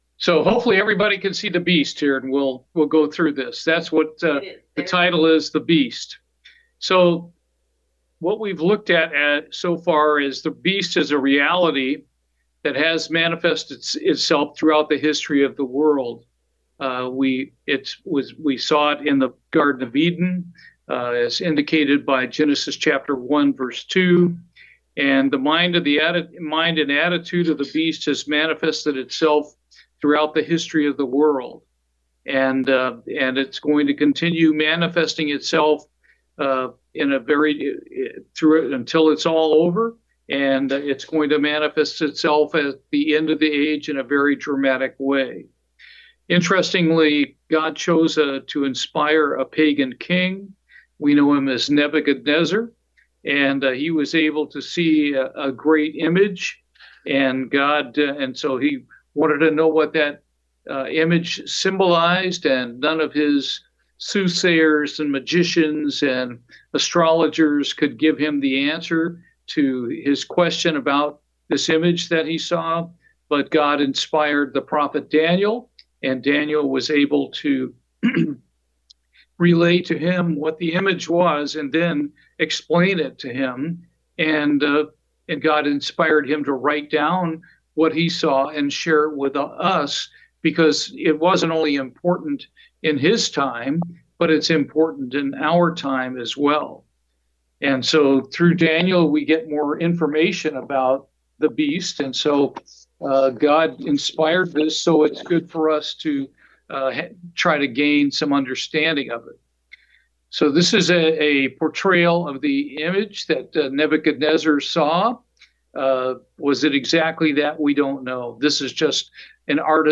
Bible Study, The Beast Chart, Part 2
Given in Houston, TX